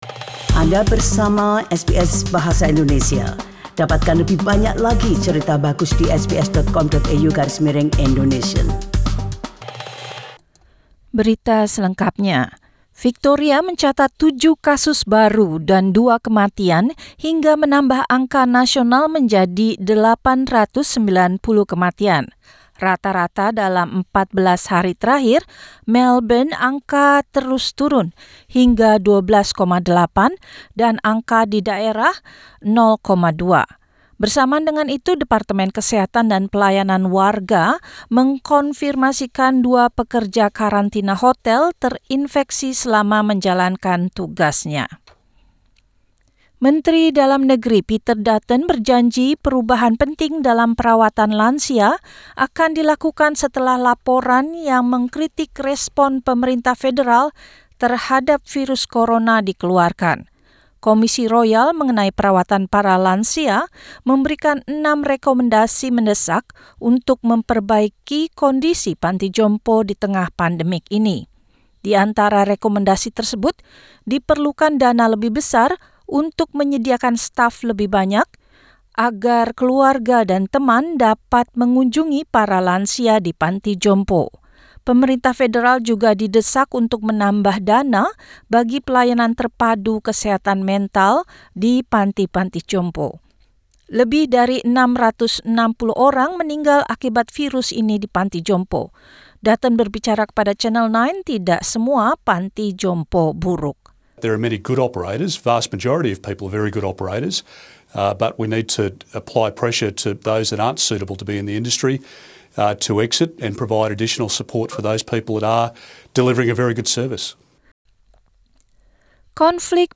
SBS Radio News in Indonesian - 2 October 2020